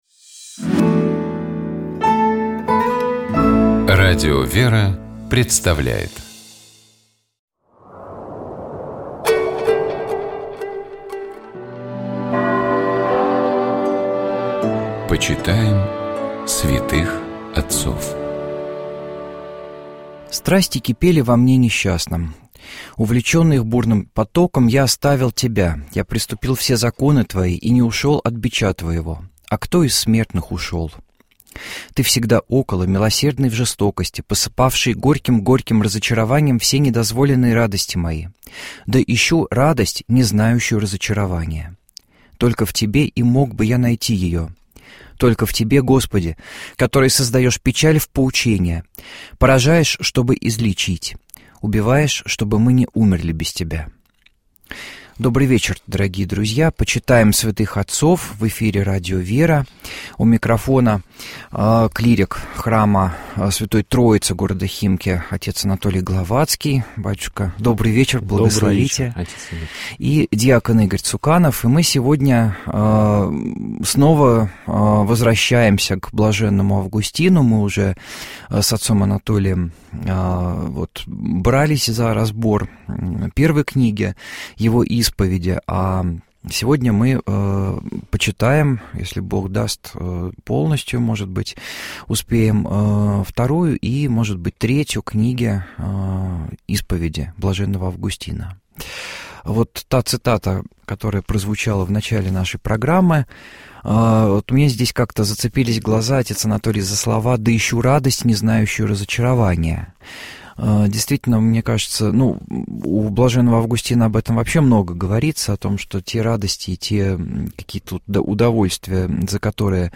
У нас в студии был